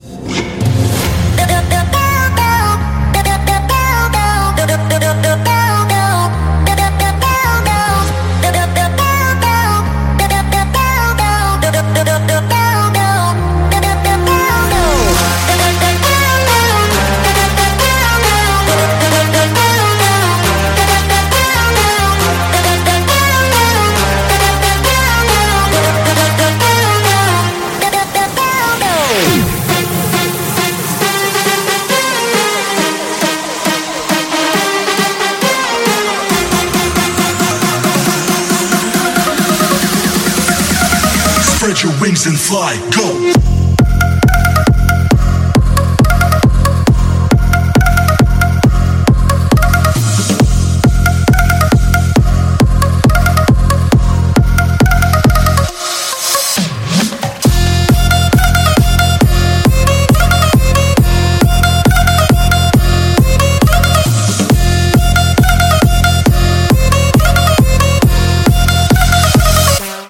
• Качество: 192, Stereo
громкие
веселые
Electronic
Big Room
забавный голос
electro house
Красивый клубняк